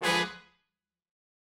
GS_HornStab-Ddim.wav